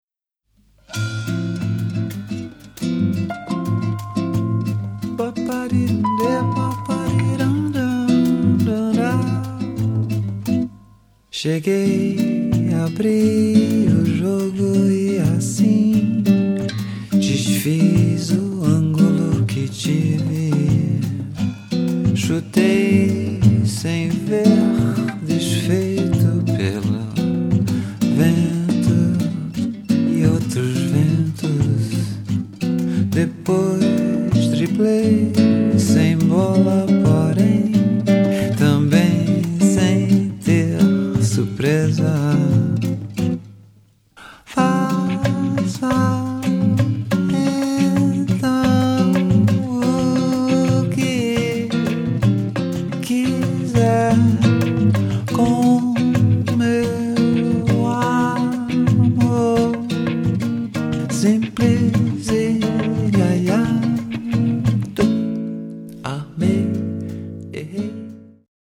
Stylisher Avantgarde-Bossa Nova